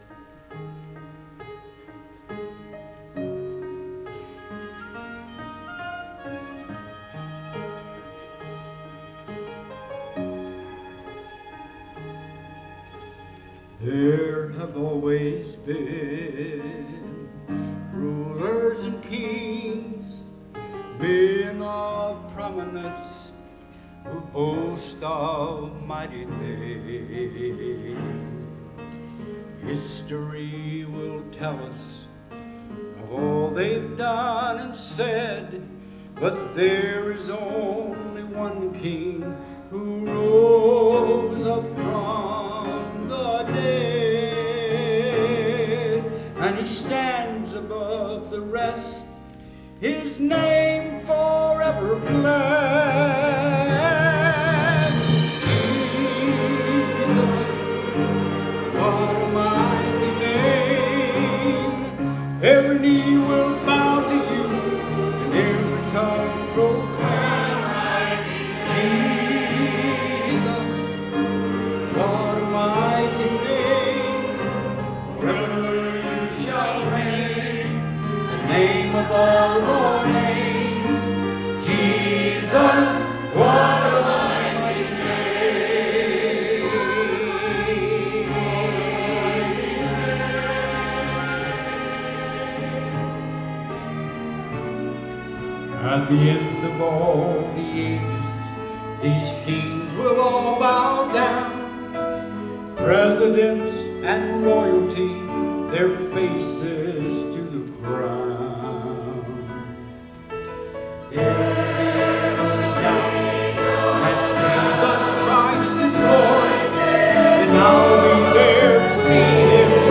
Choir and Orchestra